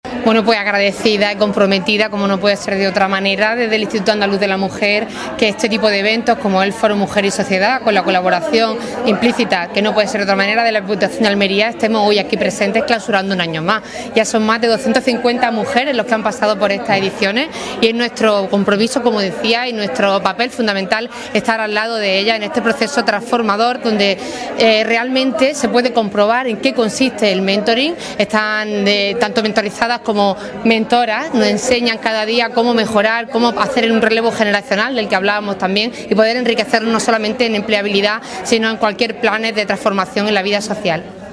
El Salón de Plenos de la Diputación de Almería ha acogido el acto de clausura de la edición número cinco del Programa de Mentoring que impulsa el Foro Mujer y Sociedad con el apoyo, entre otros instituciones, de la Diputación de Almería o el Instituto Andaluz de la Mujer.